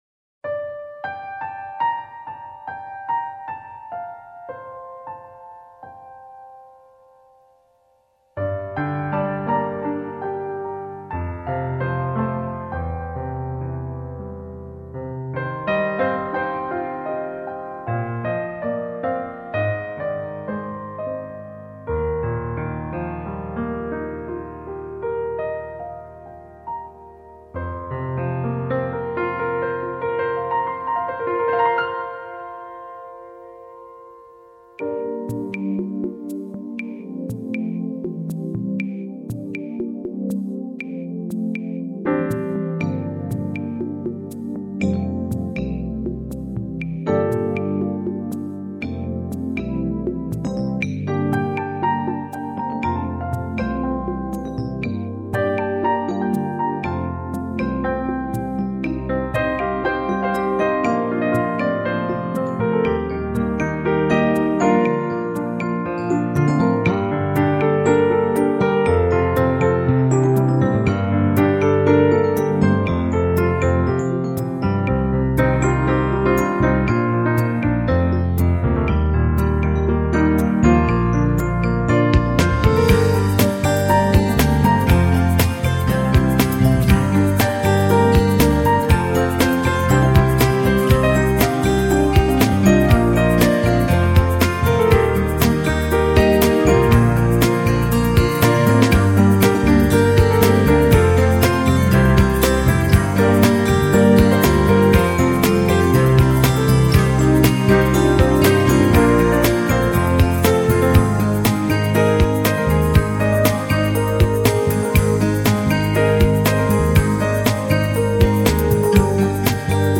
碟内十一首作品的乐器配合得天衣无缝，协奏出和谐、舒畅、飘逸乐曲。
整张专辑节奏平缓、流畅，产生出在快节奏生活中的人们暗自向往的缓冲因素。
体現浩瀚宇宙、广渺山林的新世纪无压音乐